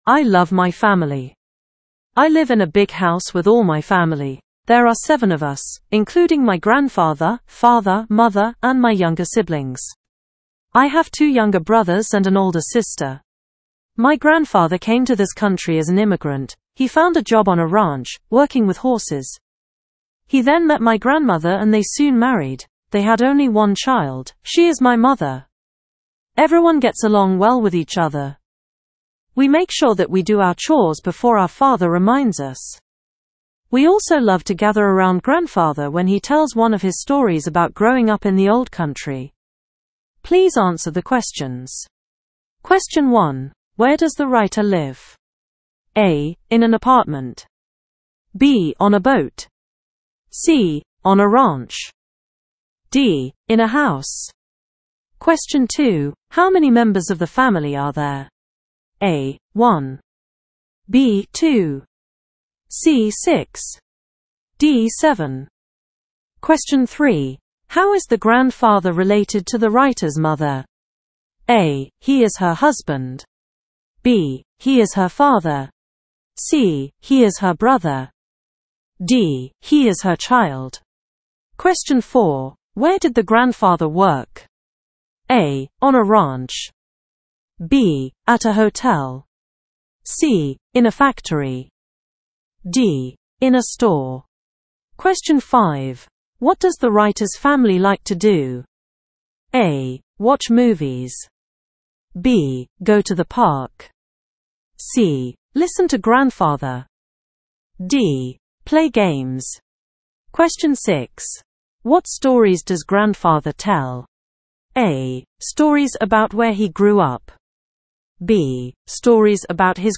Inglaterra